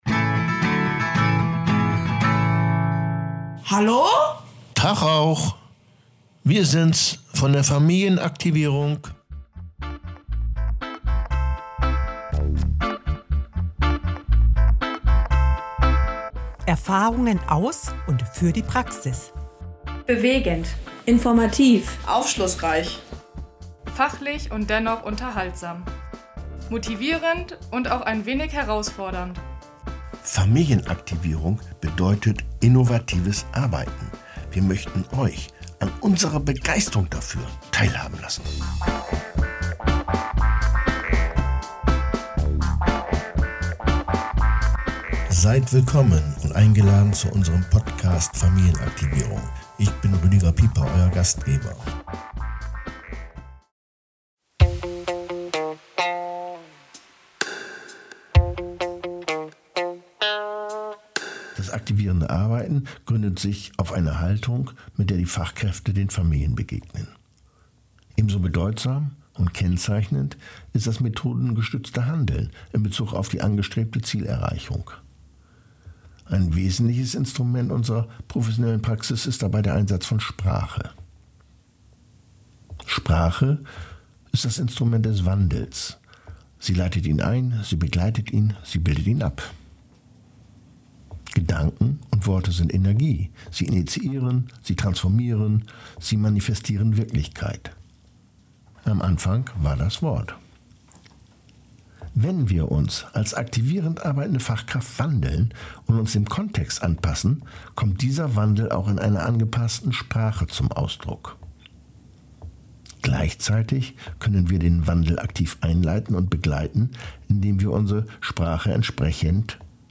Prägende Begriffe familienaktivierenden Arbeitens. Poetry Folge 1: Achtung